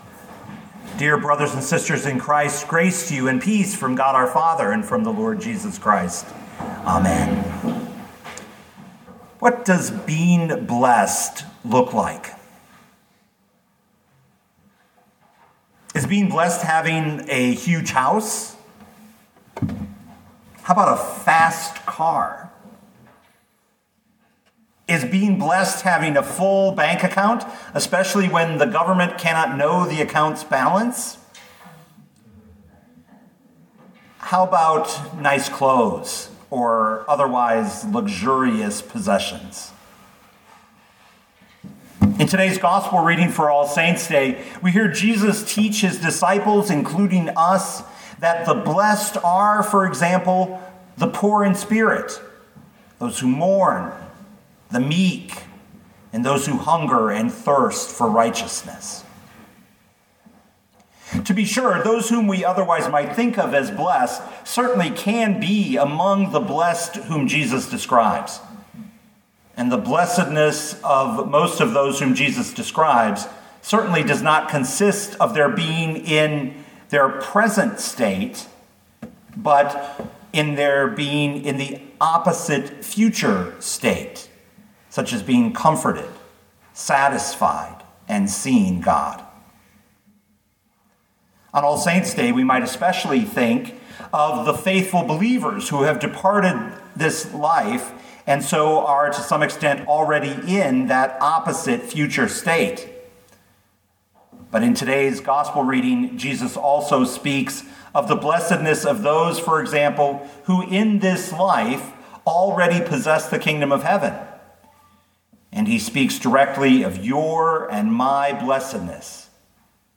2021 Matthew 5:1-12 Listen to the sermon with the player below, or, download the audio.